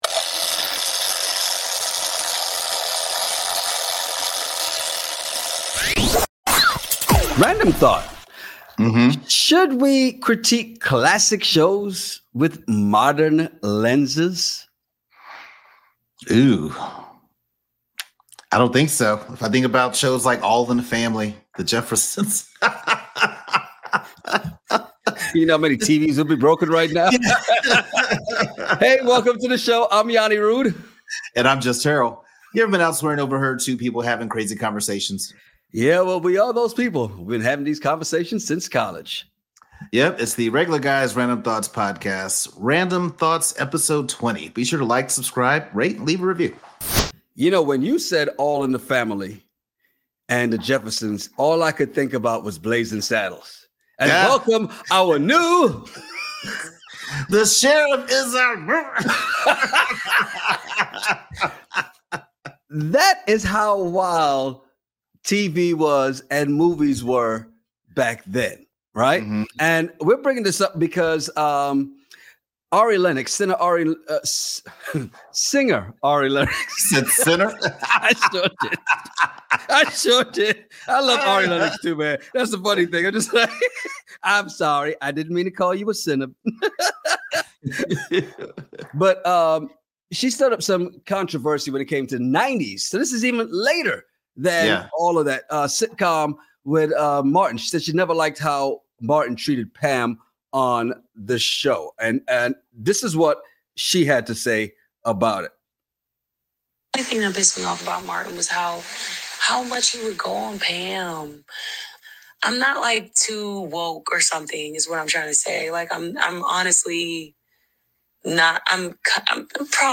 Play Rate Listened List Bookmark Get this podcast via API From The Podcast Ever been in a bar or restaurant and overheard guys in a random conversation?